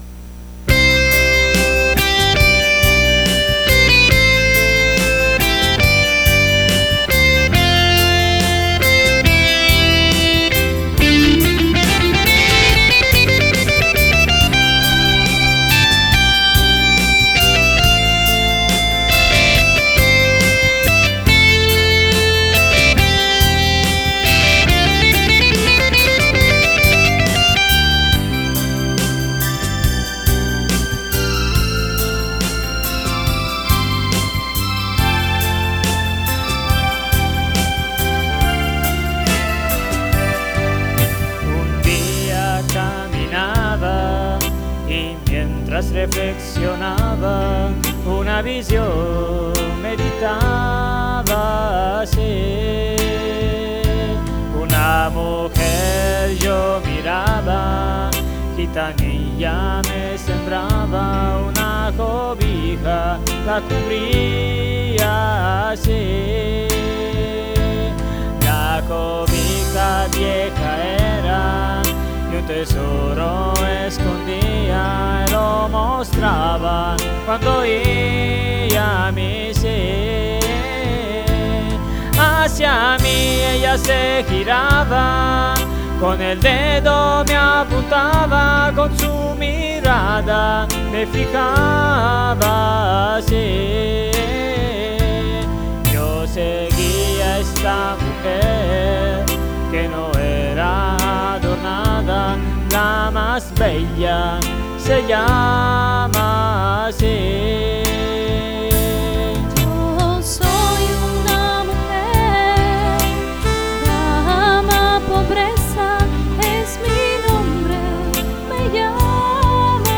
notas de guitarra